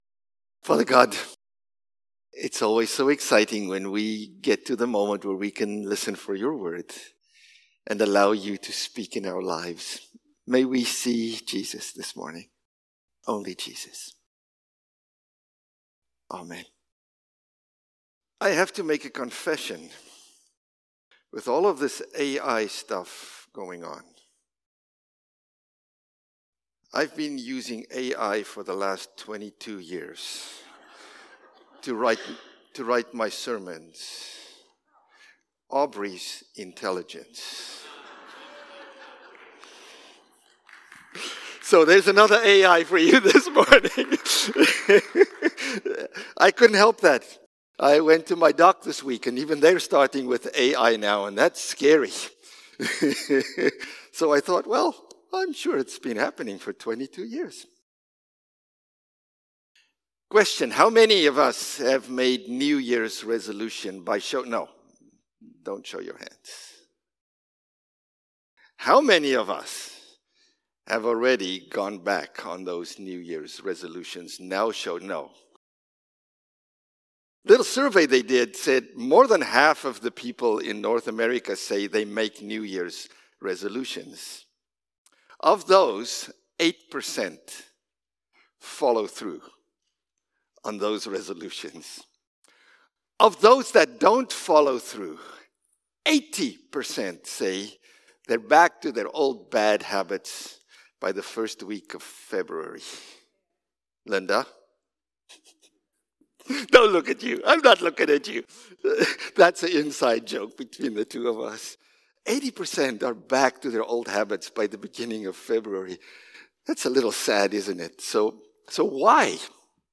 January-5-Sermon.mp3